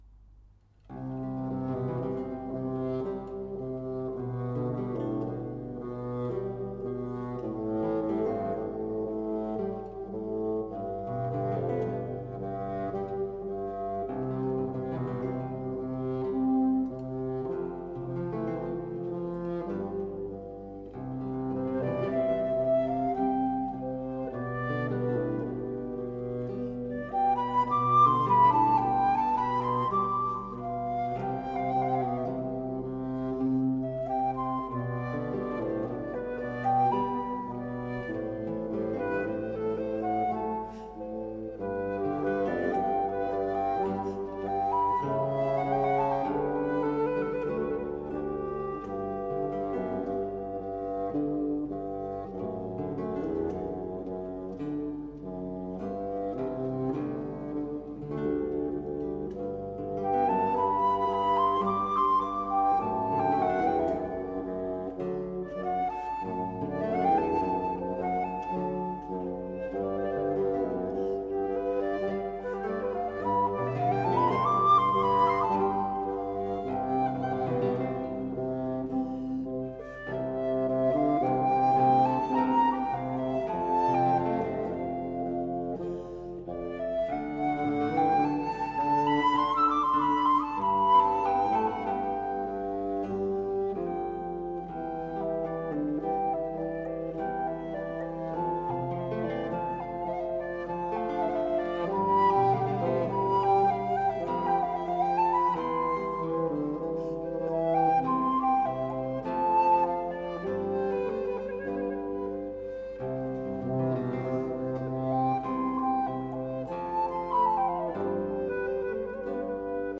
Flute concerto
Flute concerto ~1690 (Baroque, Classical, Romantic) Group: Solo concerto A concerto for solo flute and instrumental ensemble, customarily the orchestra.